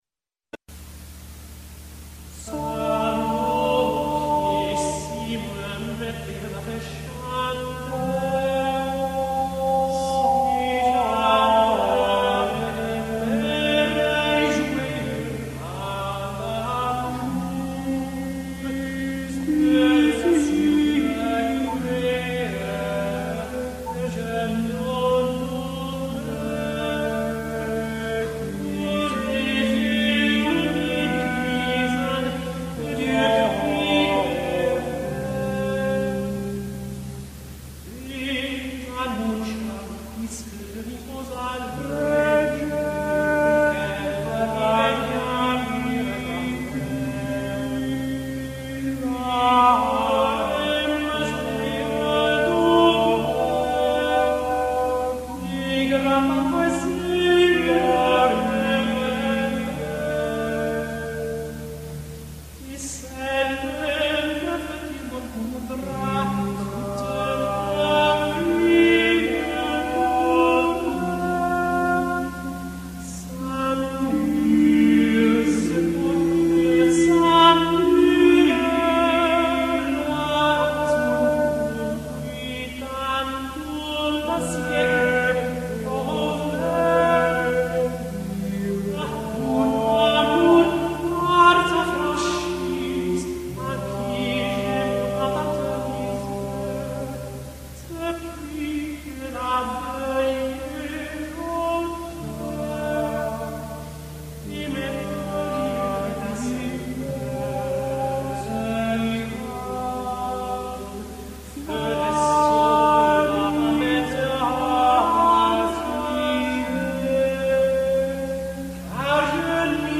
Petronian motet
Petronian motet ~1295 - ~1305 (High Medieval) France Group: Motet Further division of the triplum, the motetus and triplum move toward light and elegant expression, and a lack of concern for principles of proper textual accentuation. A highly complex genre, given its mixture of several semibreve breves with rhythmic modes Petrus la Cruce - Amours qui (12 of 25)